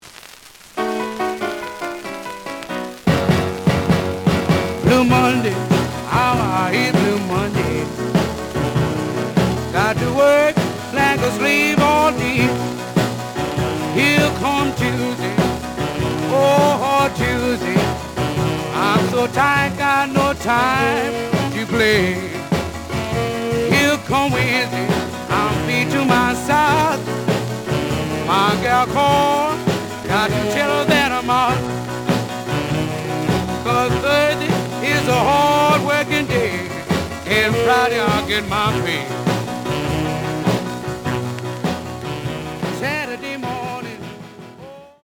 The audio sample is recorded from the actual item.
●Genre: Rhythm And Blues / Rock 'n' Roll
Some click noise on later half of A side due to scratches.